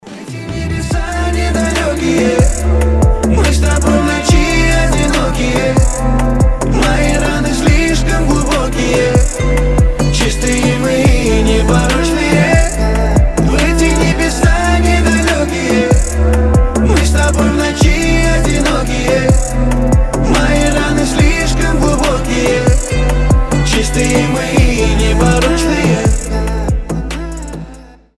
• Качество: 320, Stereo
лирика
красивый мужской голос
мелодичные
дуэт
восточные